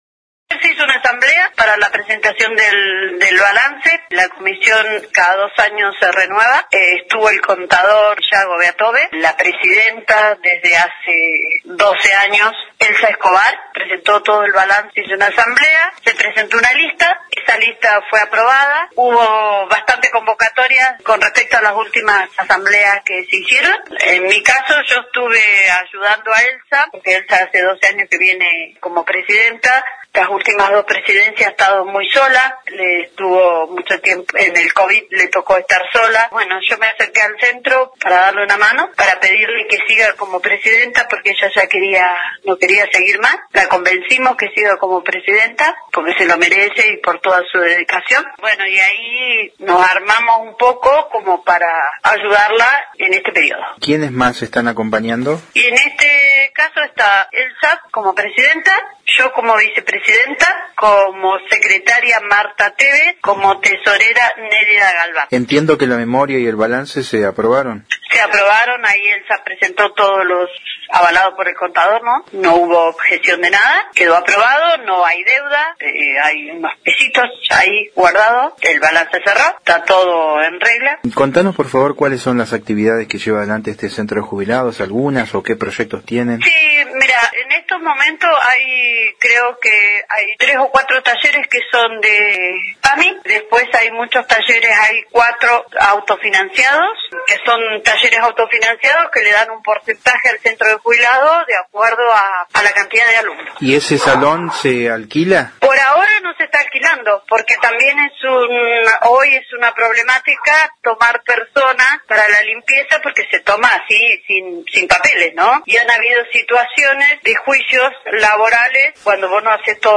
Una de las mujeres que integran esta nueva comisión directiva